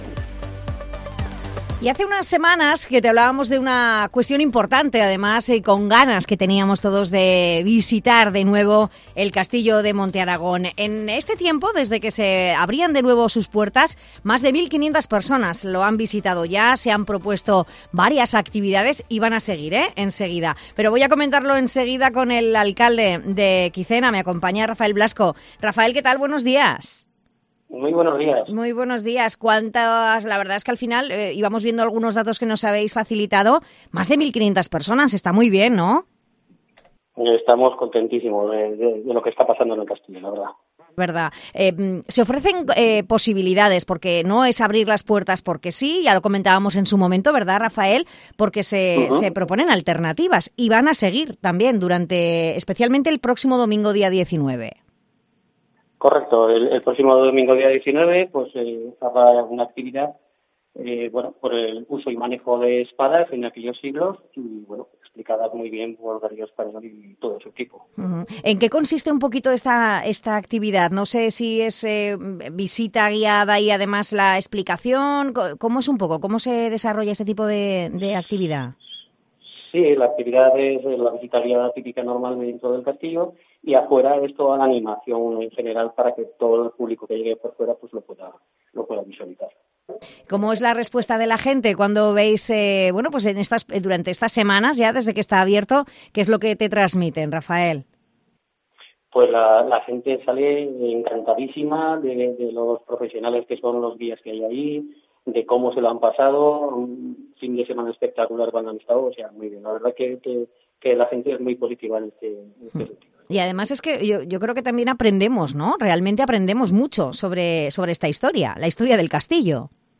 El alcalde de Quicena, Rafael Blasco sobre las visitas guiadas al castillo de Monteargón